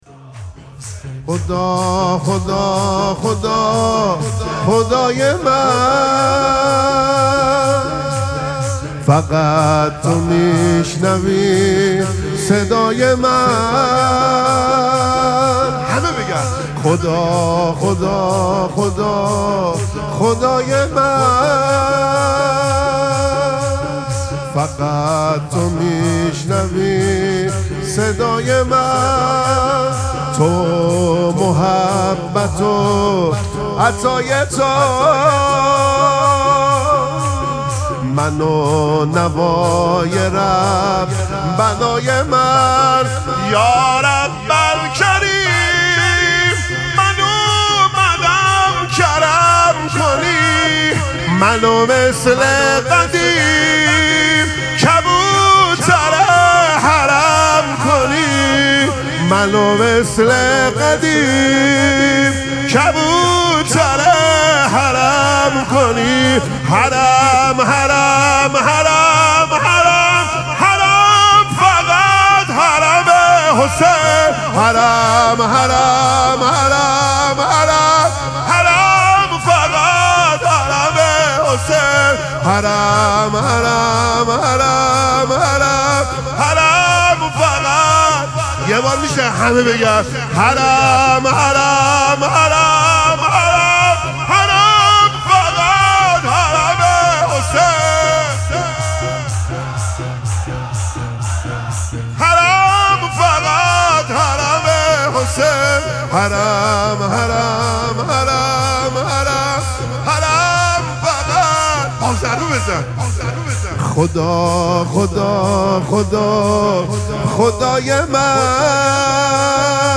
مراسم مناجات شب سیزدهم ماه مبارک رمضان
حسینیه ریحانه الحسین سلام الله علیها
شور